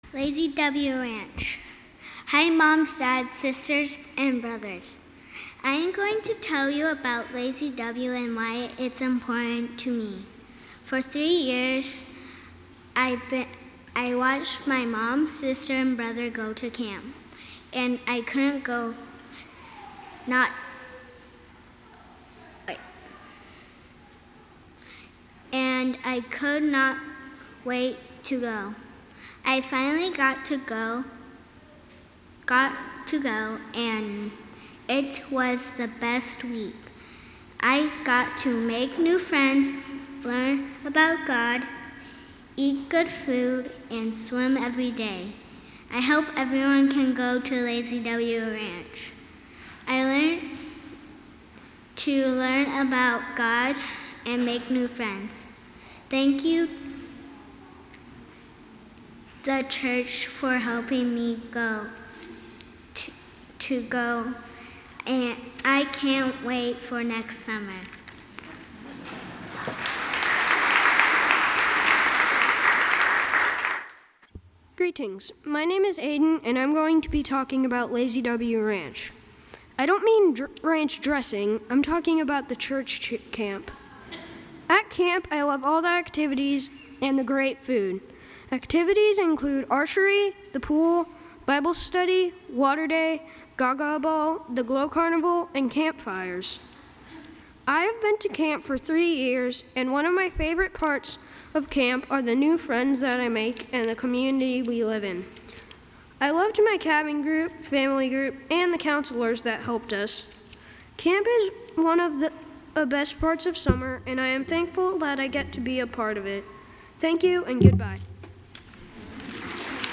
Children’s Sabbath 2018 – 9:30
Children